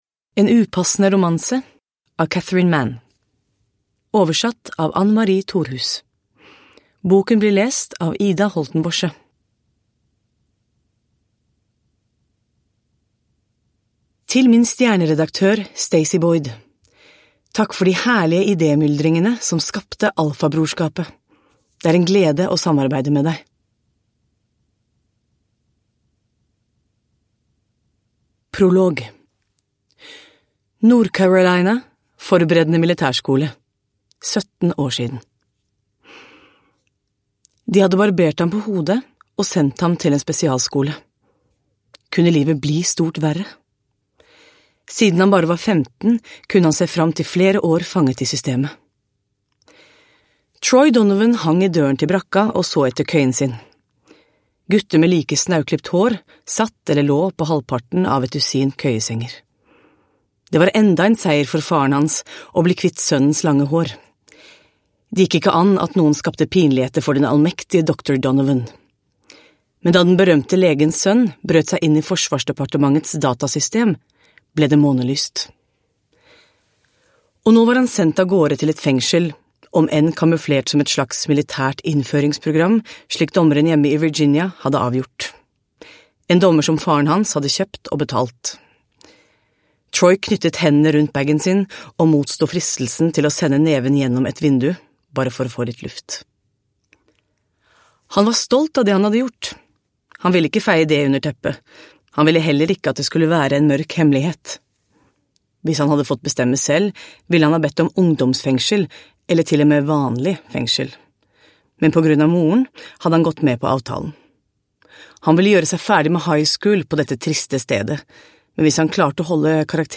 En upassande romanse – Ljudbok – Laddas ner